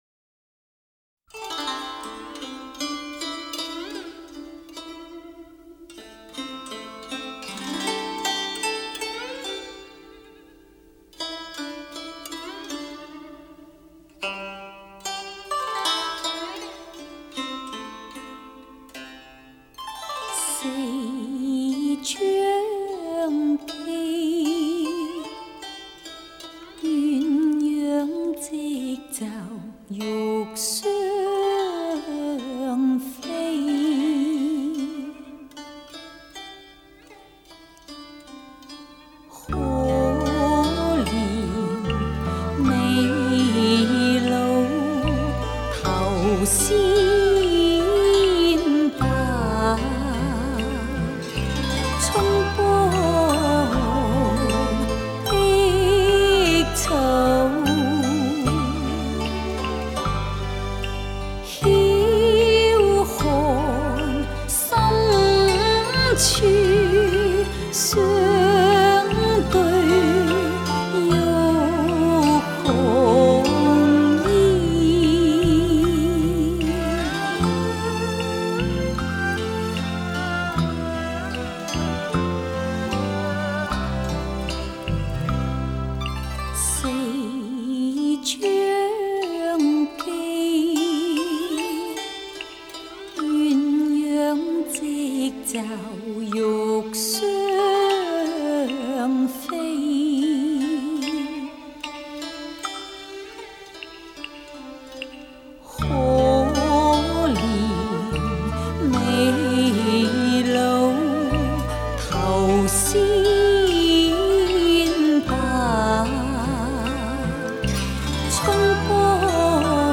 超重低音!!!